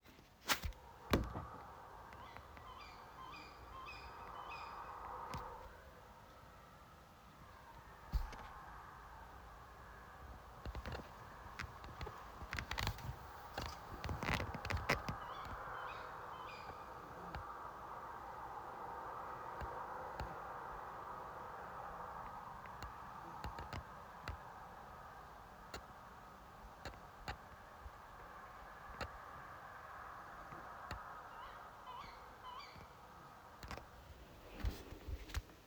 Птицы -> Совообразные ->
серая неясыть, Strix aluco
СтатусСлышен голос, крики